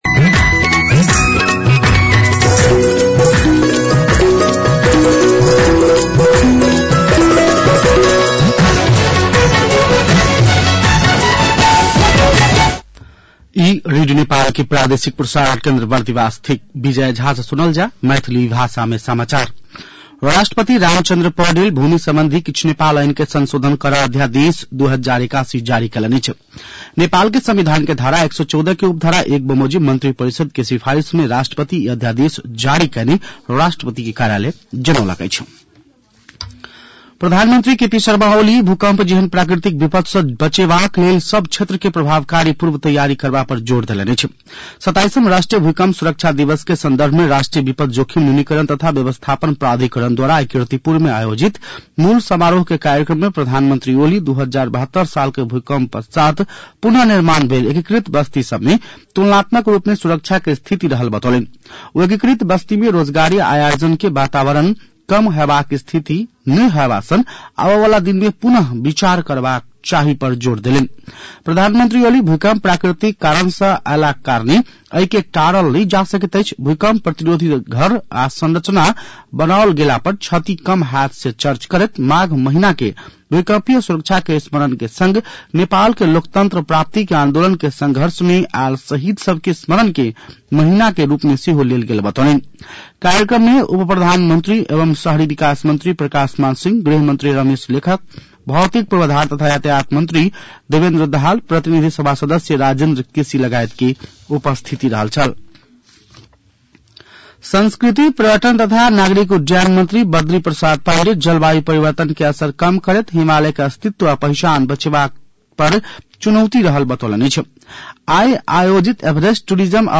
मैथिली भाषामा समाचार : ३ माघ , २०८१
Maithali-News-10-2.mp3